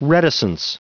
Prononciation du mot reticence en anglais (fichier audio)
Prononciation du mot : reticence